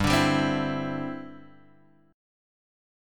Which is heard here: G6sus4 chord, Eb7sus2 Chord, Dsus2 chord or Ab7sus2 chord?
G6sus4 chord